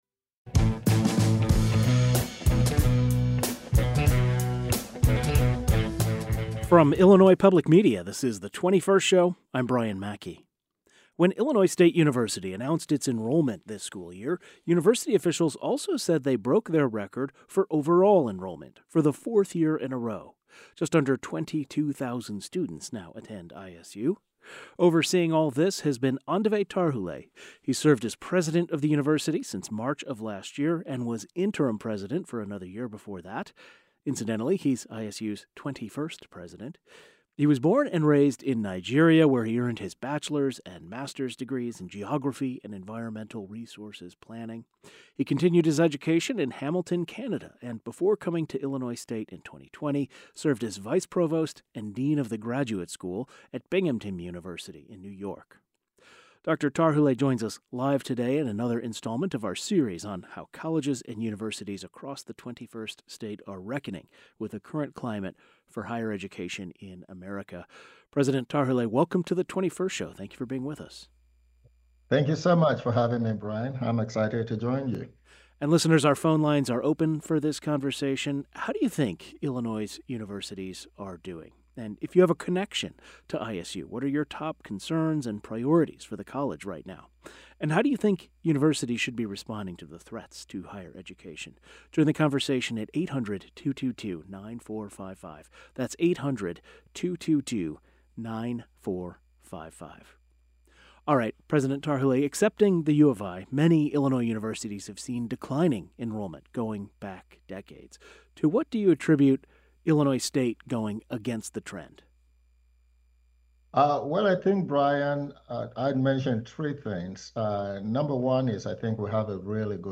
We continue our series of interviews with higher education leaders with Aondover Tarhule, president of Illinois State University in Normal.